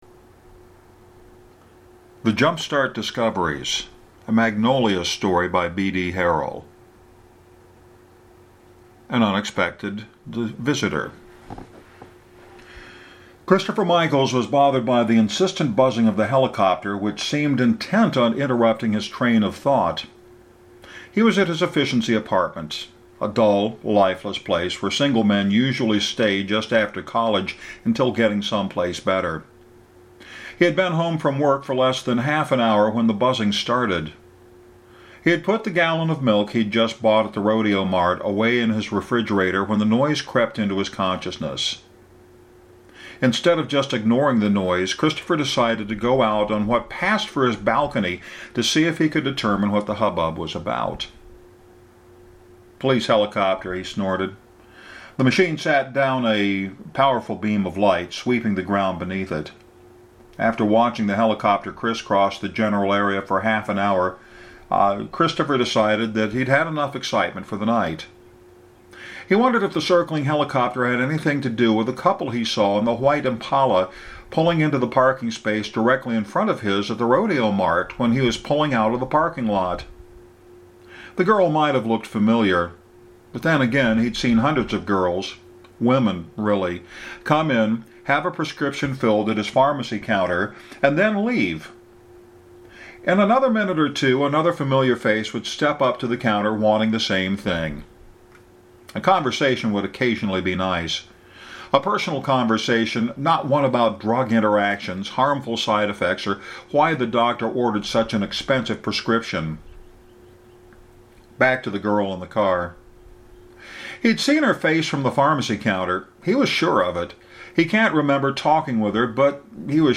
Since that day is here, the narration starts again – from the beginning just as previously stated.